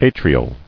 [a·tri·al]